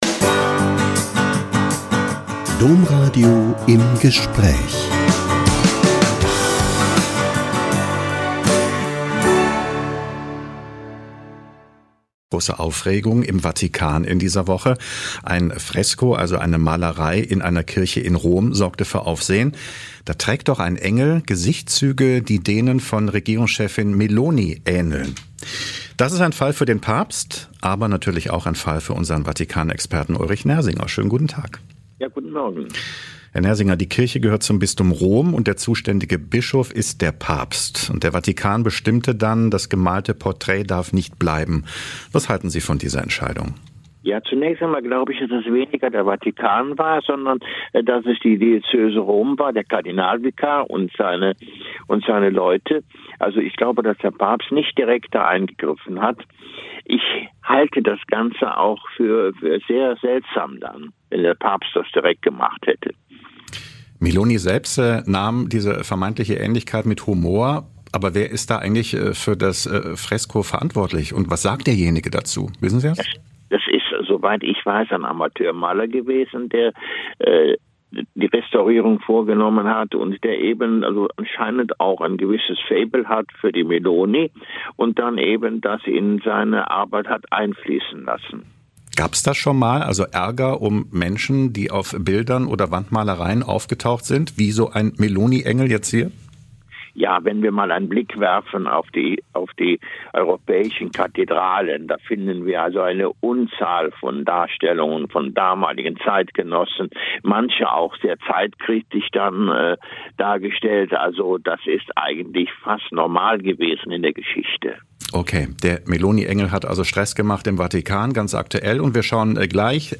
Vatikanexperte erläutert historische Kontroversen um Meloni-Fresko